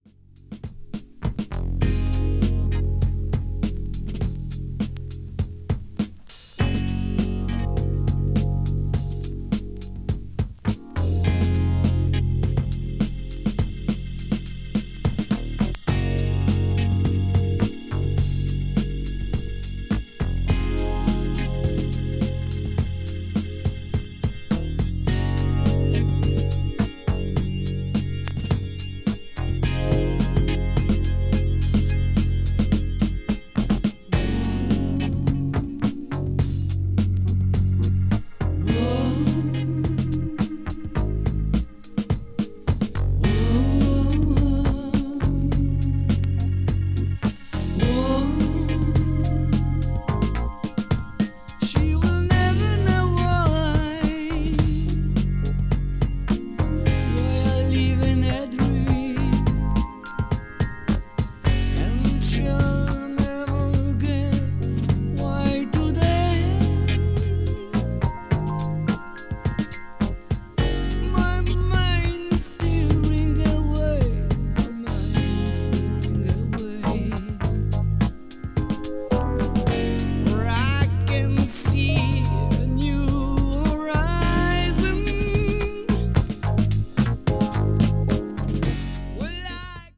両面ともにいくつかのパートで構成されたトータル・コンセプト・アルバムで、様々な展開をみせるシンフォニックな組曲。
jazz rock   mellow rock   progressive rock   symphonic rock